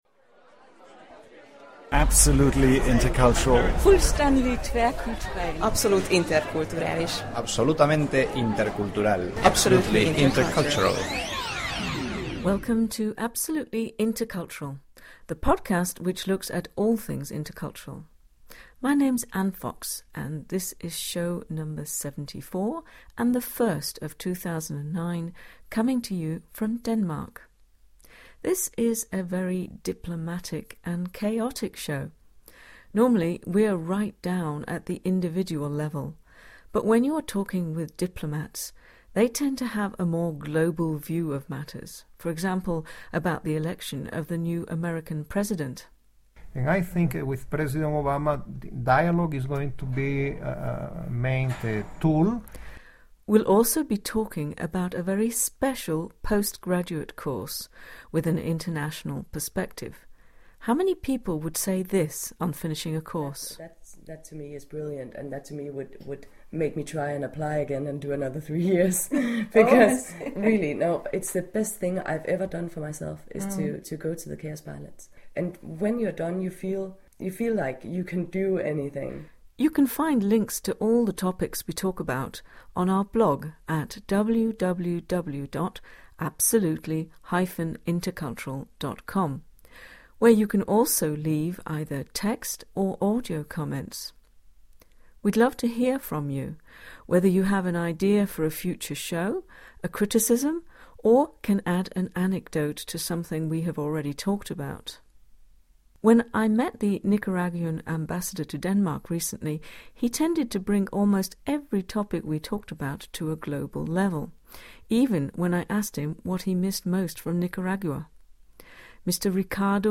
absolutely essential: When I met the Nicaraguan Ambassador for Denmark recently he tended to bring almost every topic we talked about to a global level, even when I asked him what he missed most from Nicaragua.